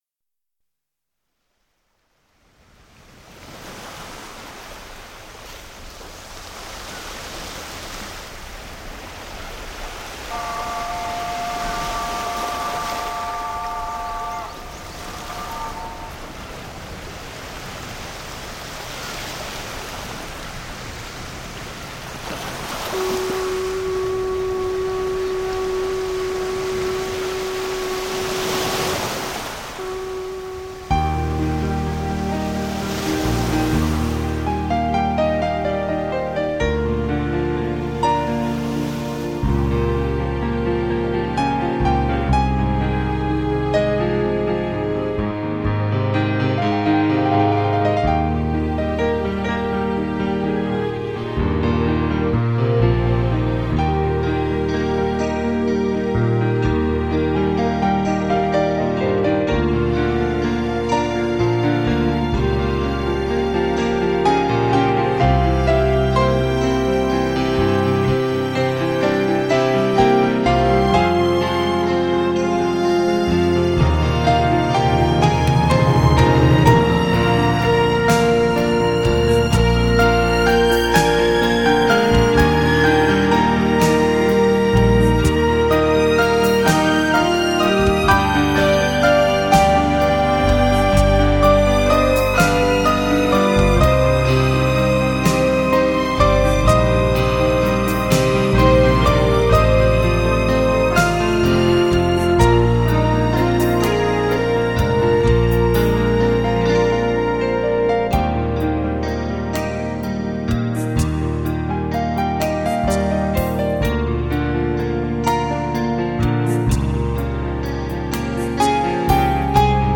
磅礴的清新韵律
海面的珊瑚礁组成的小岛边的浪花，有节奏地鸣响出深沉的“哗哗”声，似蛟龙戏水。
乐奏的技巧使美妙的乐曲和大海常溶为一体，为你来一次尽情的海边漫步。